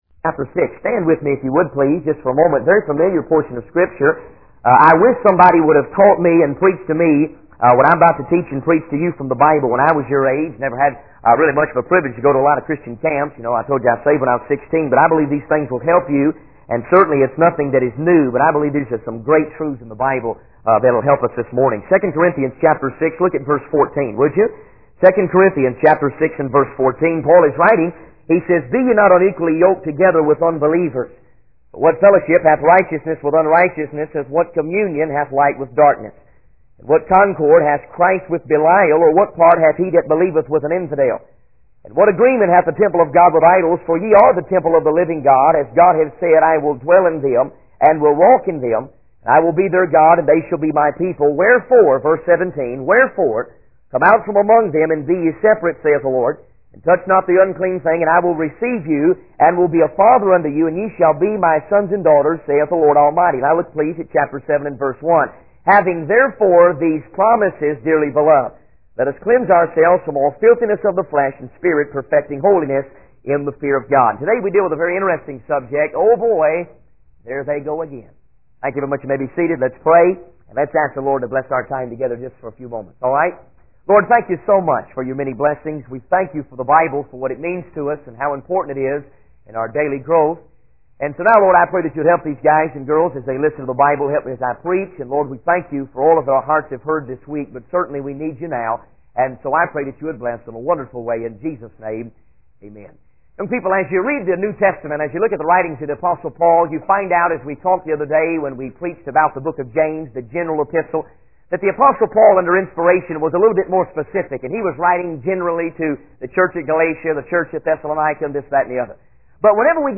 In this sermon, the preacher begins by expressing gratitude for the Bible and its significance in our daily growth. He emphasizes the importance of having a humble and obedient attitude towards God. The preacher highlights the transformation that occurs when one becomes a new creature in Christ, leaving behind their old sinful ways.